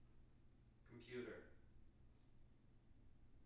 wake-word
tng-computer-304.wav